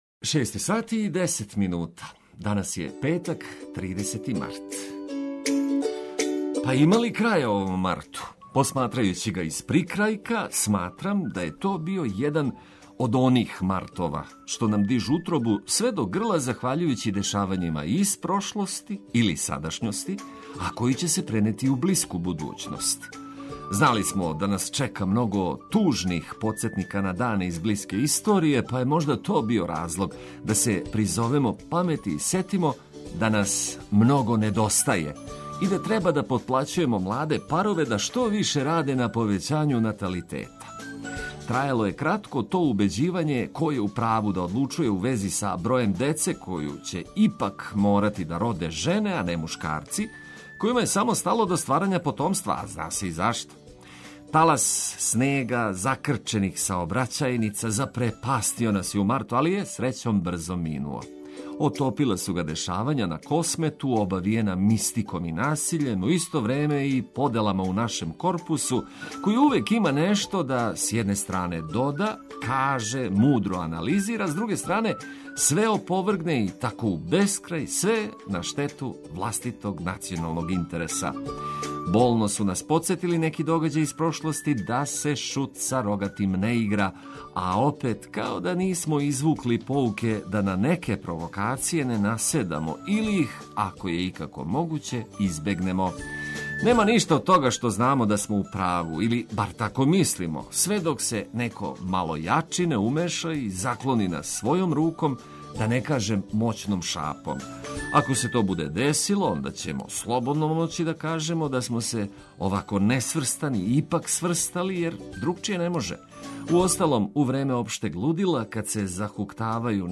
Важне вести и музика за лепше буђење.
Све информације уз веселу, пролећну музику и сјајно расположену јутарњу екипу на једном месту.